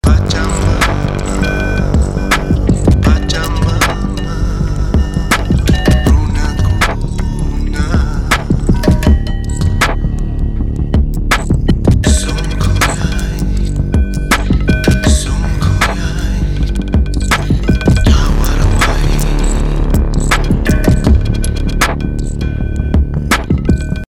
Alle drei zusammen mit den Poppy Pluckers, Native Instruments India und weiteren Versen des Sängers aus Peru:
Dadurch spielen sie weit vorne, zerteilen das Klangbild regelrecht und schaffen scharfe Konturen.
Dieser stammt von Spectrasonics Trilian und wummert tief und fett vor sich hin.
Der Bass weist nur zum Taktbeginn einen markanten Filter-Sweep auf.